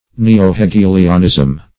Neo-Hegelianism \Ne`o-He*ge"li*an*ism\, n.